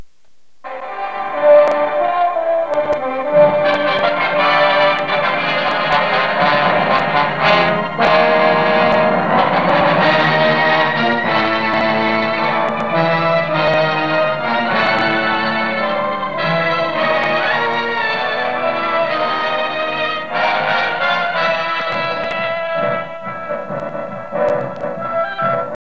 Play introduction music  to set the mood